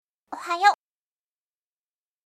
📌 Friendly & Casual → A simple and casual way to greet someone in the morning.